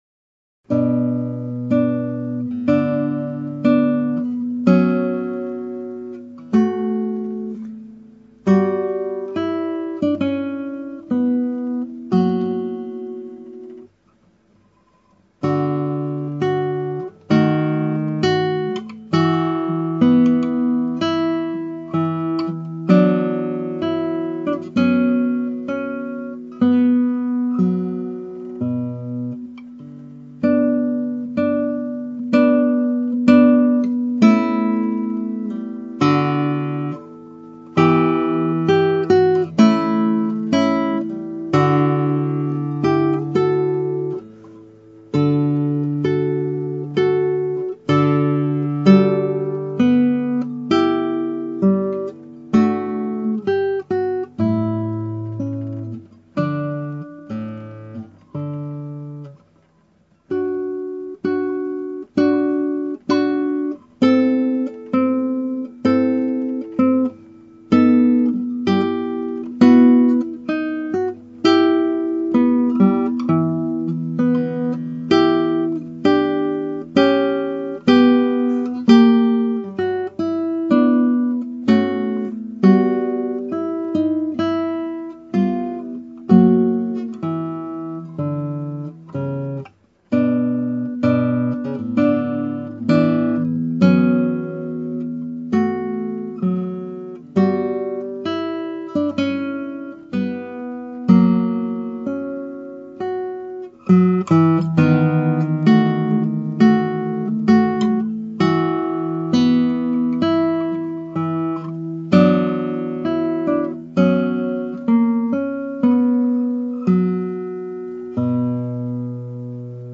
(アマチュアのクラシックギター演奏です [Guitar amatuer play] )
速さ指定はAndanteですが、今回、私はかなり遅い速度で弾いてみました。
堂々とした感じが似合う曲だと思いこの様にして見ました。